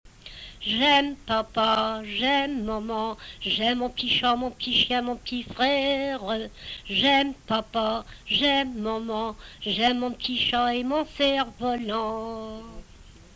Emplacement Miquelon